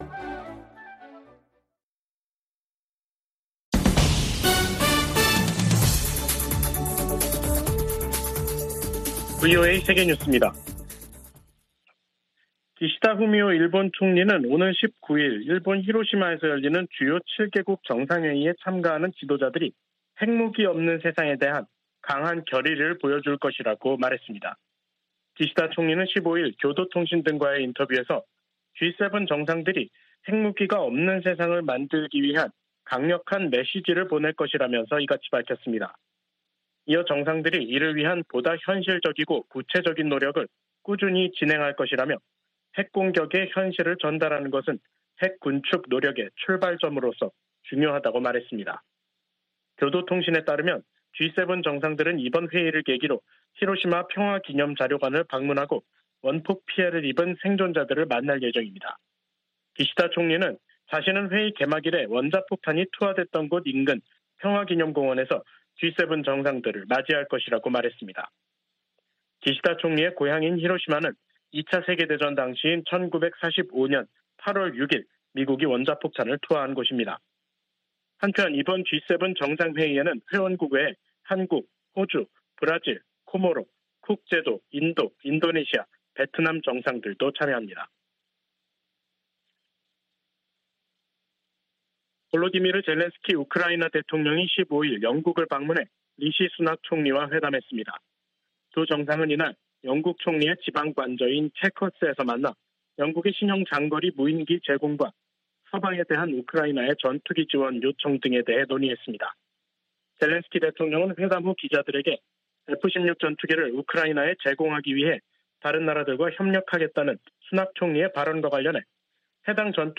VOA 한국어 간판 뉴스 프로그램 '뉴스 투데이', 2023년 5월 12일 3부 방송입니다. 윤석열 한국 대통령이 일본에서 열리는 G7 정상회의를 계기로 서방 주요국 지도자들과 회담하고 강한 대북 메시지를 낼 것으로 보입니다.